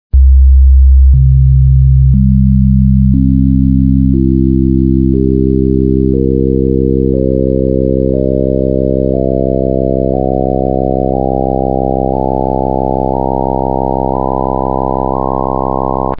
Schließlich runden Überlagerungen der ersten 4 bzw. 16 bzw. 32 Partialtöne in den Hörbeispielen